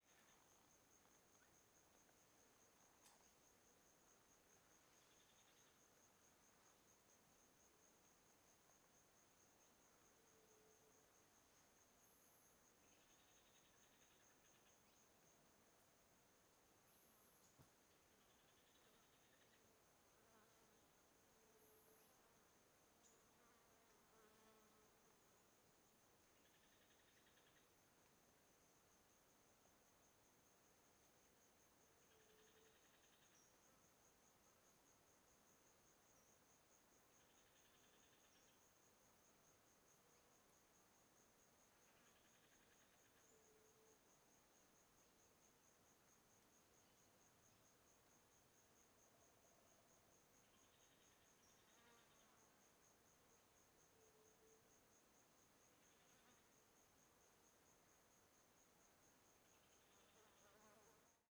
CSC-05-124-OL- Mata fechada com passaros e insetos.wav